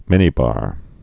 (mĭnē-bär)